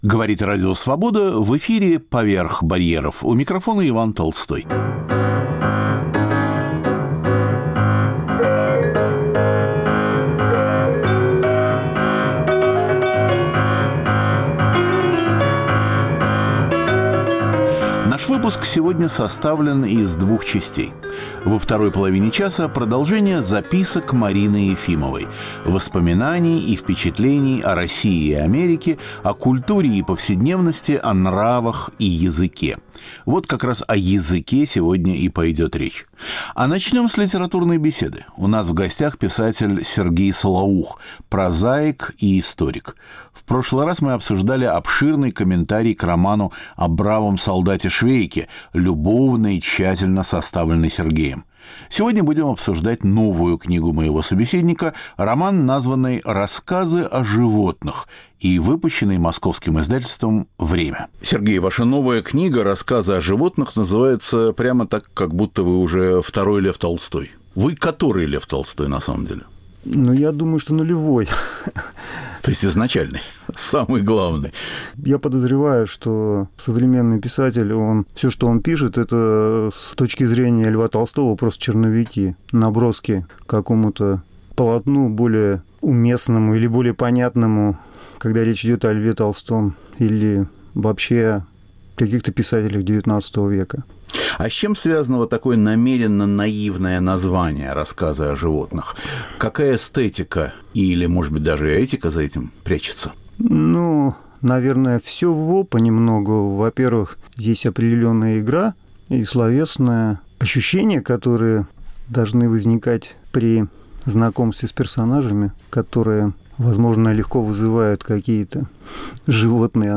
В первой части - беседа